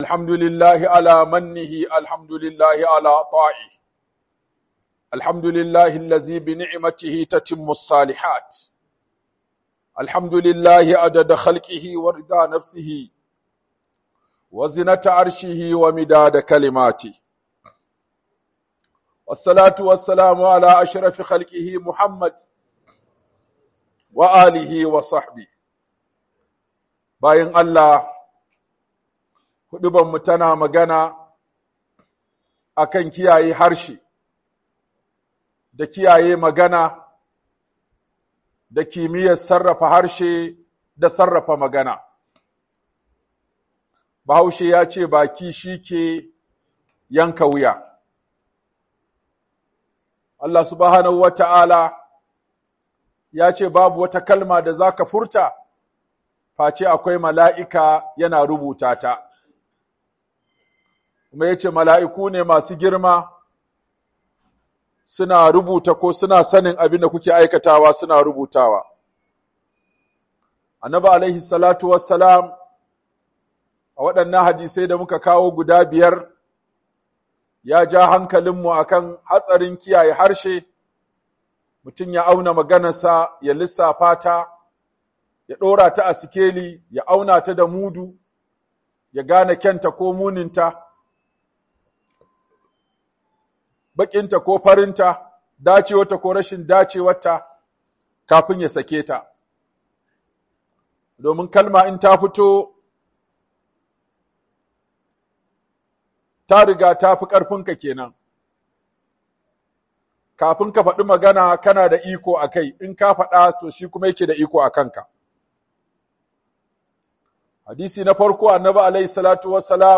Kiyaye Harshe - Huduba